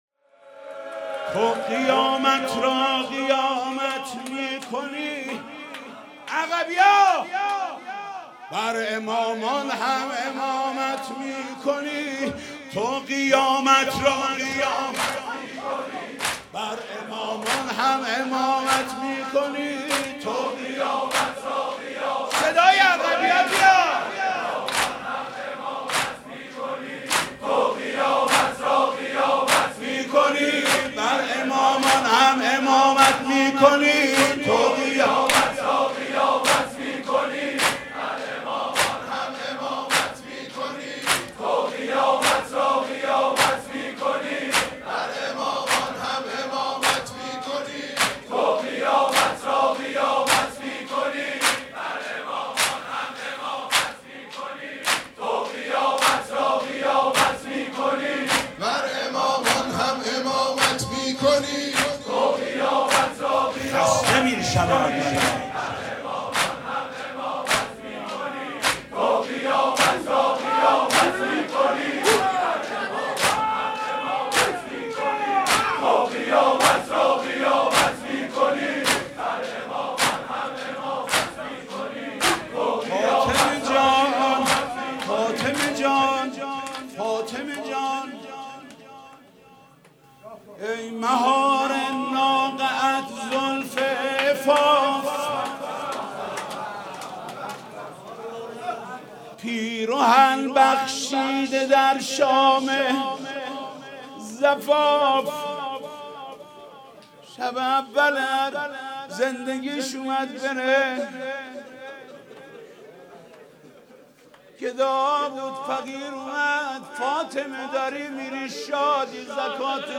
شب اول فاطمیه اول 1436 | هیات رایه العباس | حاج محمود کریمی
دودمه